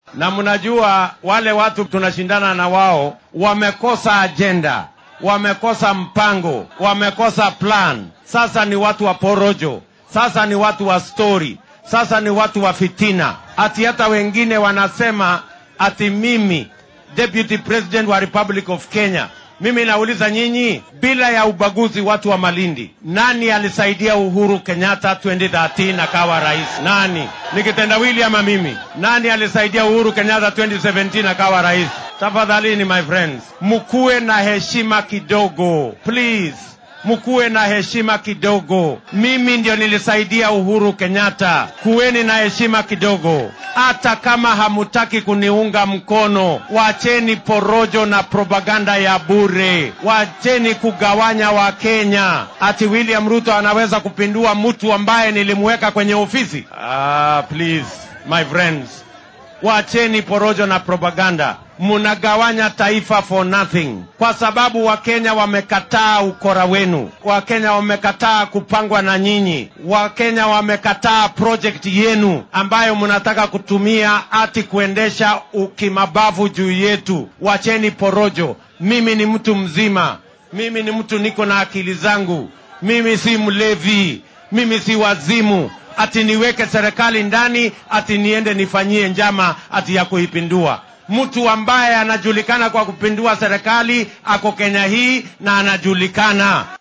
Madaxweyne ku xigeenka dalka William Ruto ayaa xilli uu shalay isku soo bax siyaasadeed ku qabtay deegaanka Malindi ee ismaamulka Kilifi ee gobolka xeebta, waxba kama jiraan ku tilmaamay inuu isku dayay qorsho uu xafiiska uga saarayo madaxweynaha wadanka Uhuru Kenyatta.